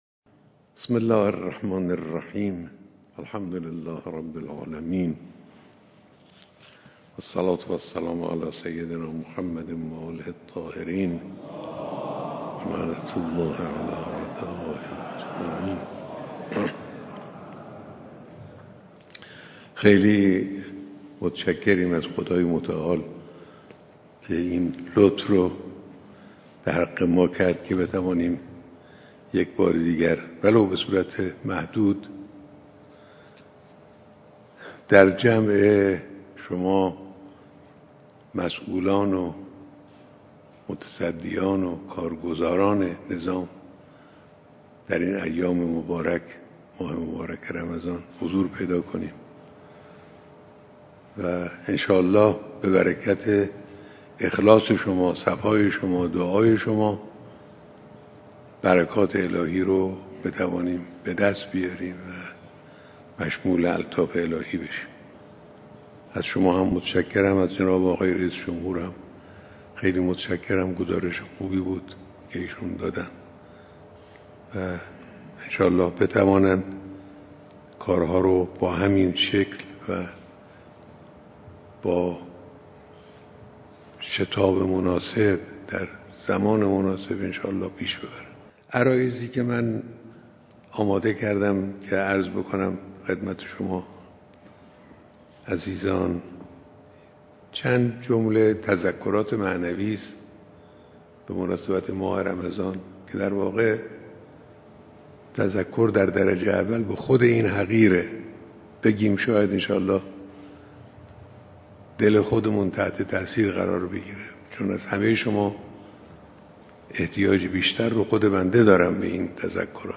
صوت کامل بیانات رهبر انقلاب اسلامی در دیدار مسئولان نظام